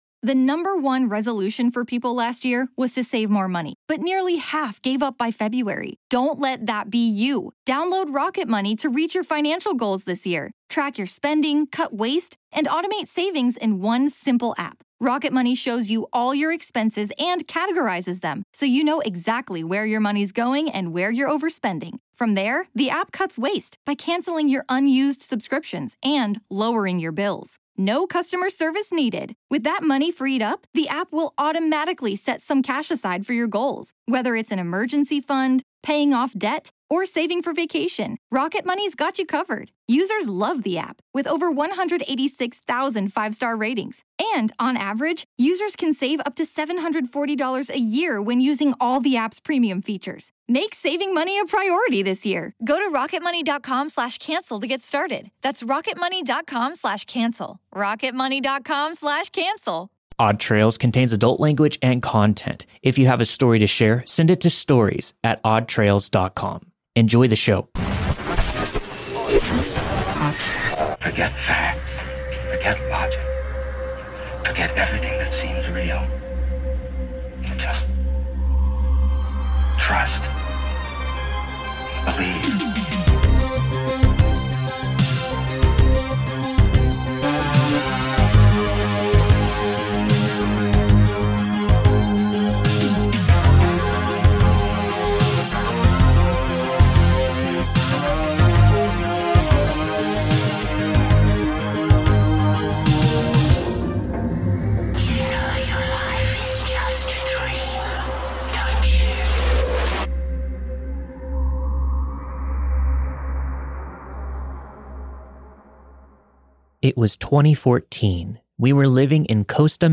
All stories were narrated and produced with the permission of their respective authors.